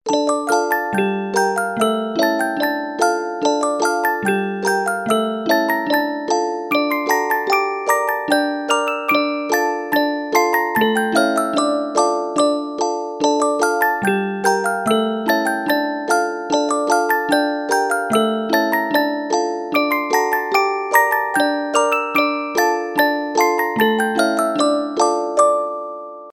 инструментальные
музыкальная шкатулка
Рингтоны без слов